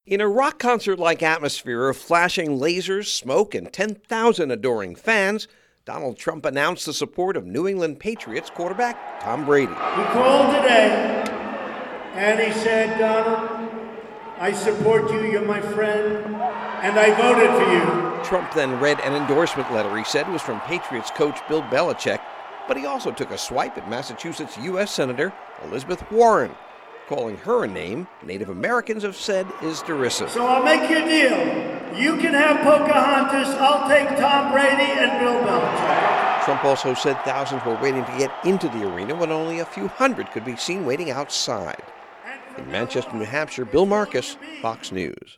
Trump rally in Manchester Monday night.